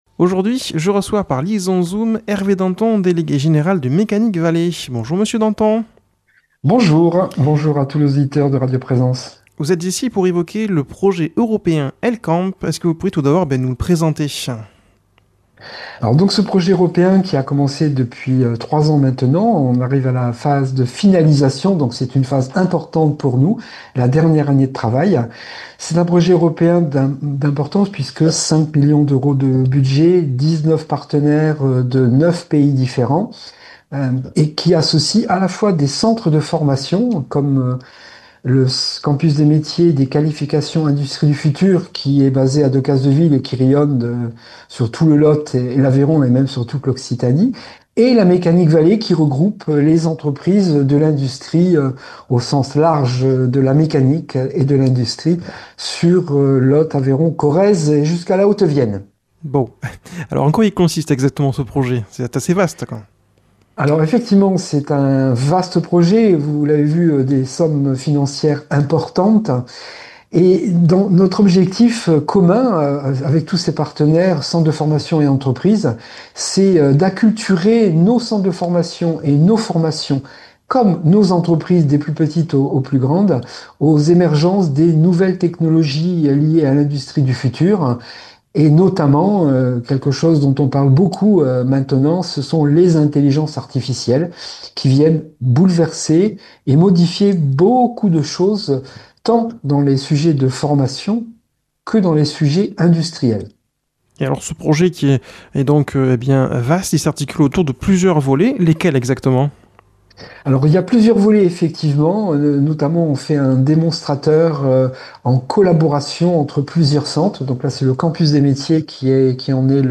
par liaison Zoom